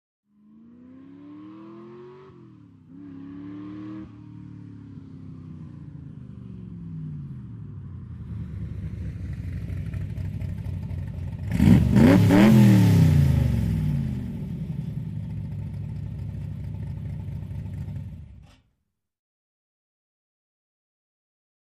Cobra; In / Stop / Off; Fast Approach In Distance, Slow To Metallic Chugging Motor, Aggressive Rev, Idle And Off. Medium To Close Perspective. Sports Car, Auto.